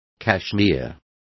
Complete with pronunciation of the translation of kashmir.